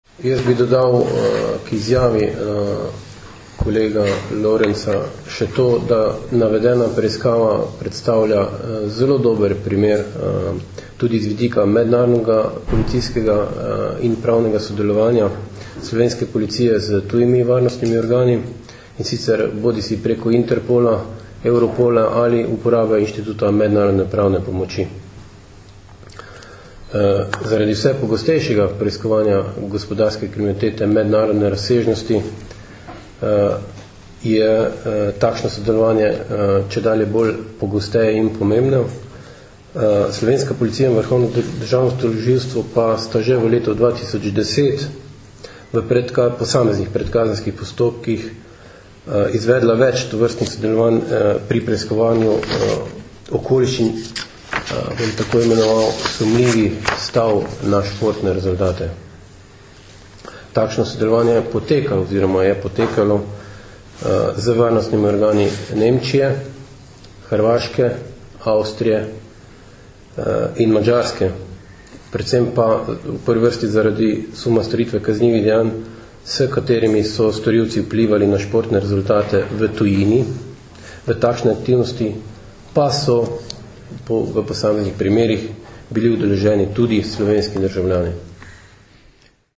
Policija - Z nedovoljenimi igrami na srečo prislužili najmanj 250.000 evrov - informacija z novinarske konference PU Maribor
Na Policijski upravi Maribor so danes, 28. aprila 2011, podrobneje predstavili preiskavo suma storitve kaznivih dejanj nedovoljenih iger na srečo.